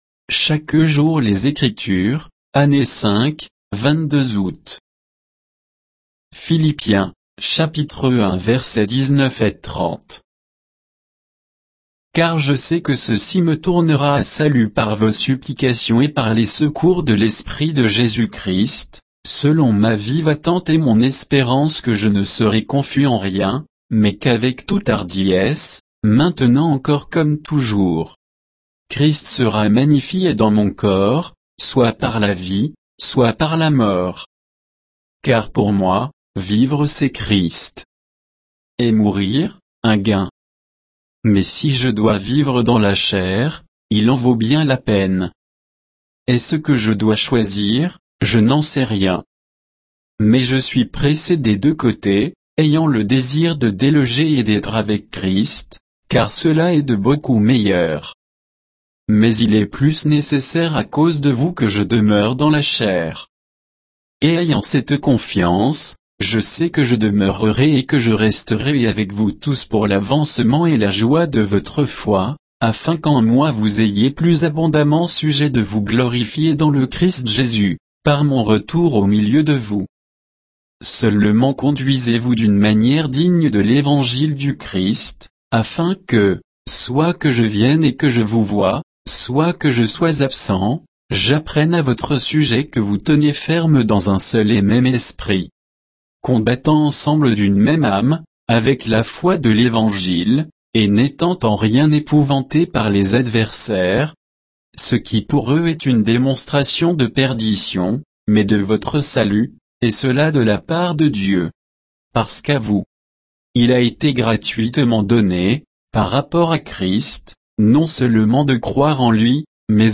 Méditation quoditienne de Chaque jour les Écritures sur Philippiens 1